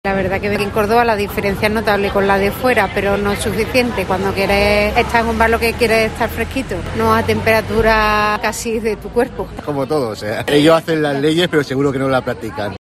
Escucha las reacciones de la calle ante el decreto energético en Córdoba